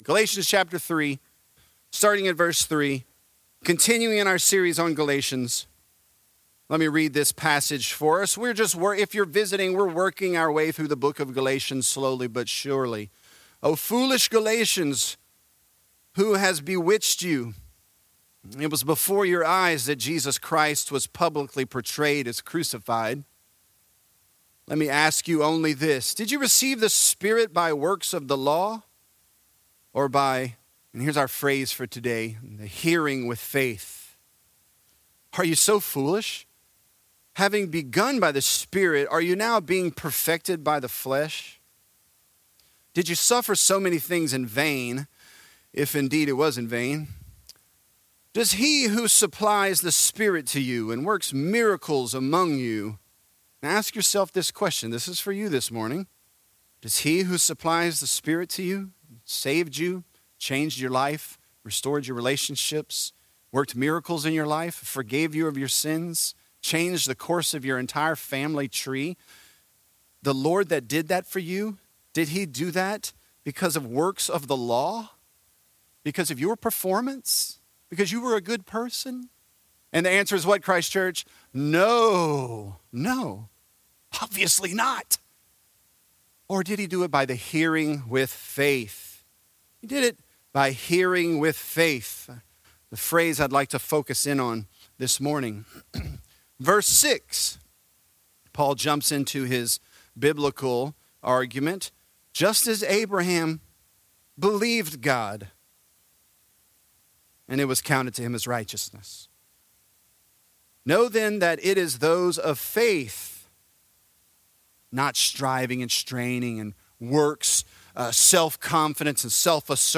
Galatians: Hearing with Faith | Lafayette - Sermon (Galatians 3)